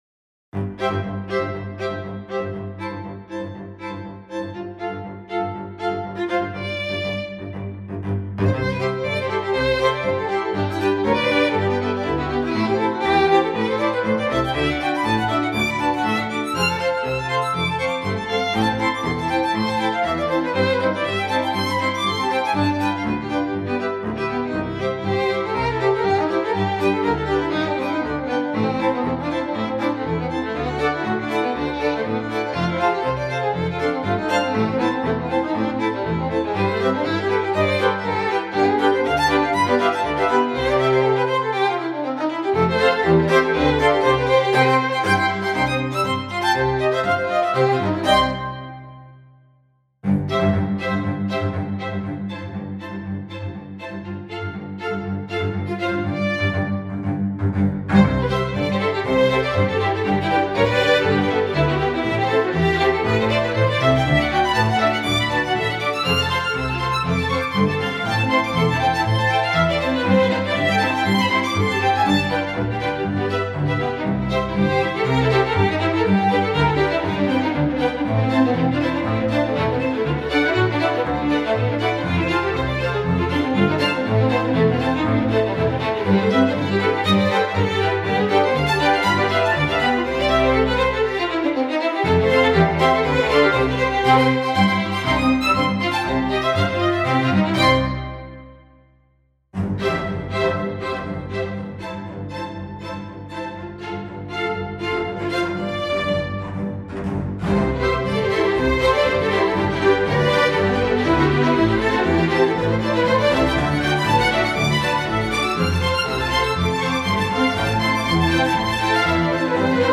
Compare the characters Solo- Chamber- Orchestra-Strings:
I played once this piece with all of the three libraries. But keep in mind that you only listen to some short note samples and that the melody is not very well done (natural).
By the way. The reverb is Altiverb, IR = Teldex Studio, 7m Stereo
BK_The_Solo_Chamber_Orchestra_VI_06.mp3